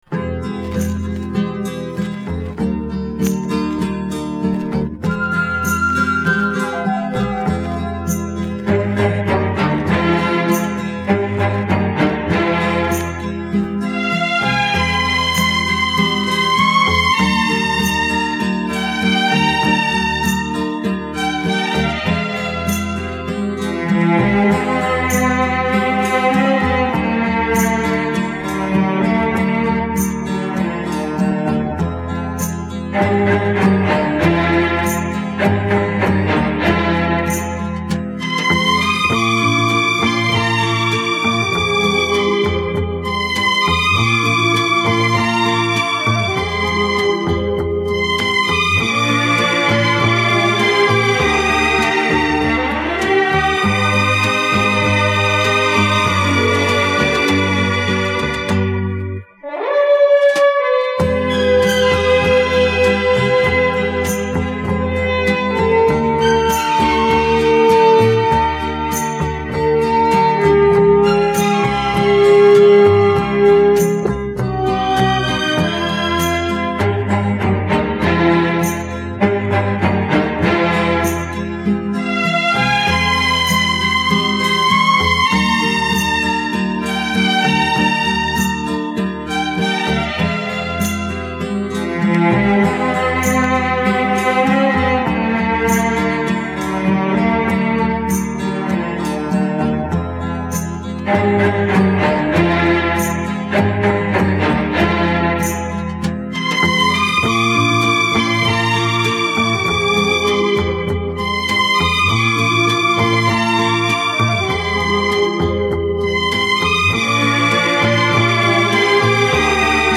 Французский флейтист, пианист и композитор.